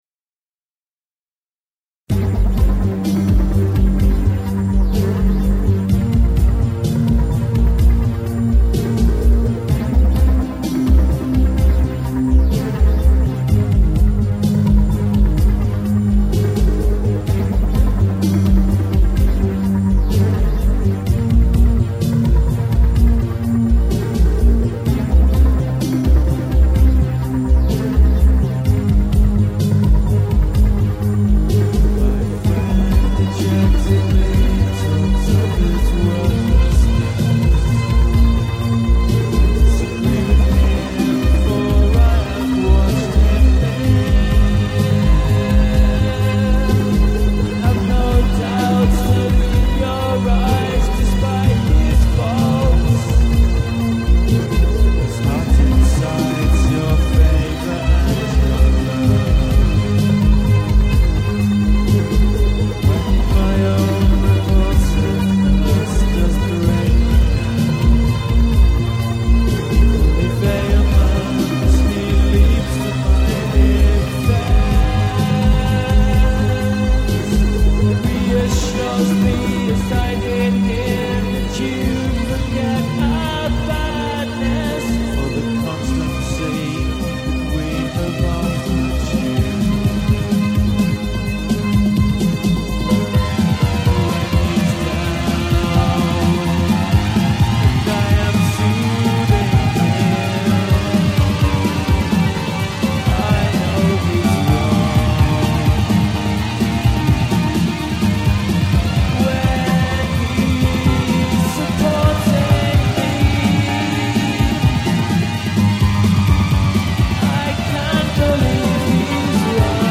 I started with an Amiga so I could have MIDI controlled sampling at my disposal and moved onto a PC to finally begin to get somewhere near professional quality.
Thus lots of instrumentals and experimental bits and pieces came into existence.